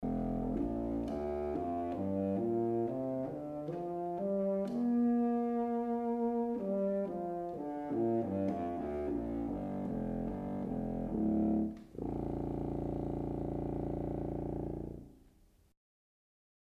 suono del controfagotto
controfagotto_suono.mp3